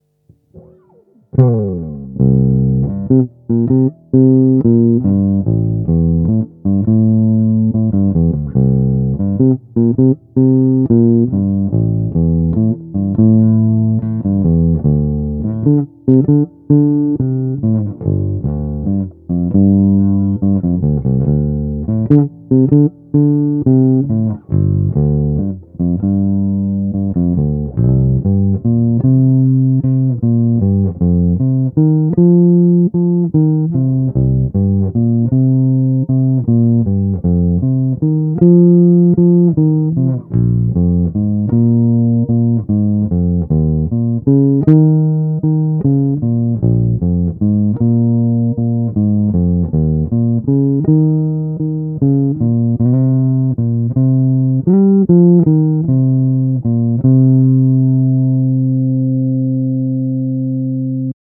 No asi klasický jazz bass J Posuďte sami z nahrávek, které jsou provedeny rovnou do zvukovky bez úprav.
Krkový, tonovka stažená